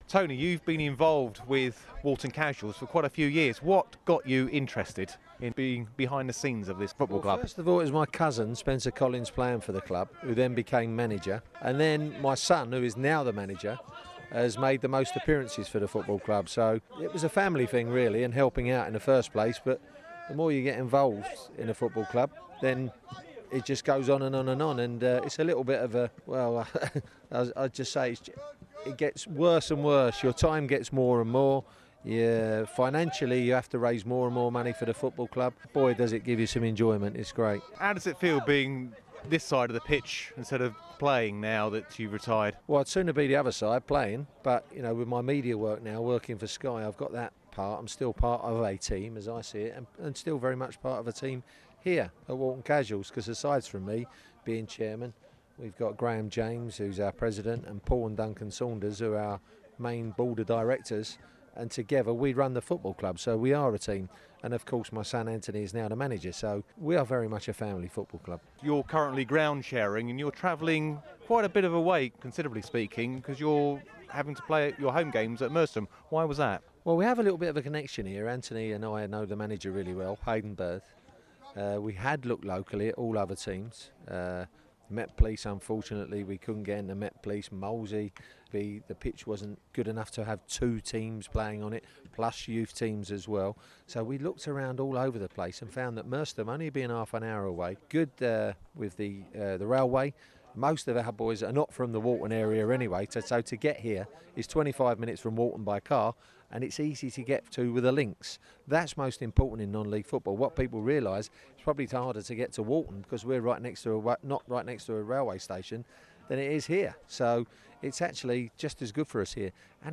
Walton Casuals chairman Tony Gale talking to BBC Surrey about his involvement in the club, ground sharing and the club's new facility’.